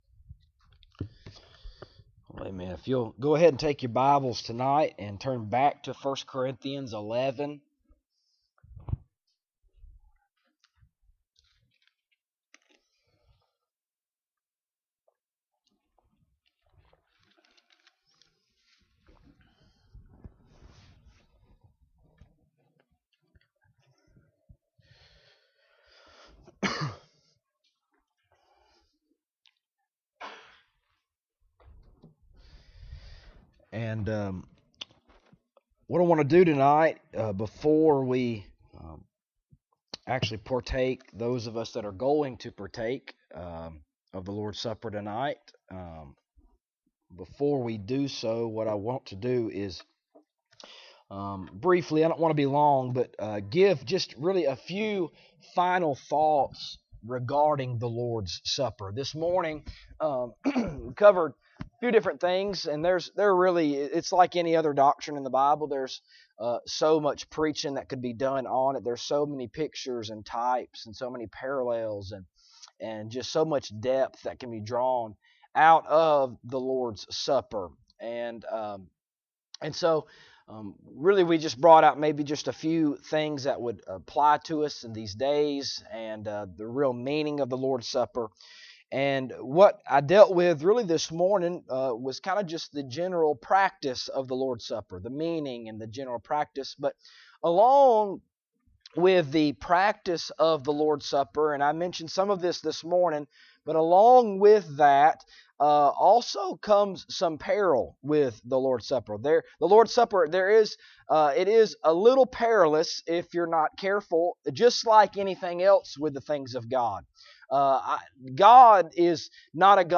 Passage: 1 Corinthians 11:23-32 Service Type: Sunday Evening Topics